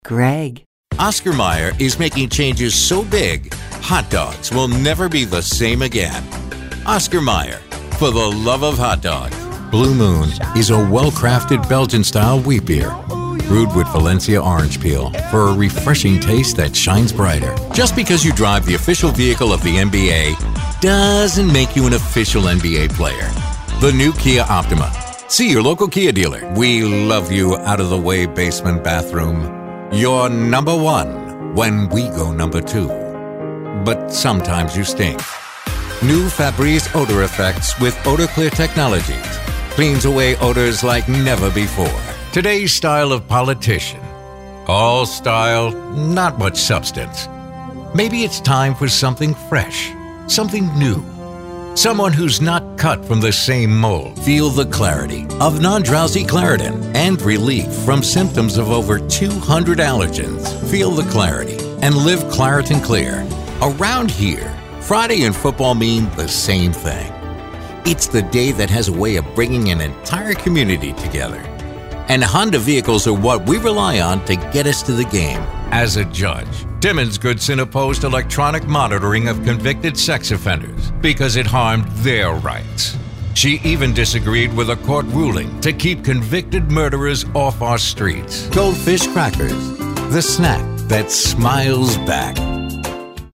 Showcase Demo
conversational, friendly, genuine, girl-next-door, millennial, real, sincere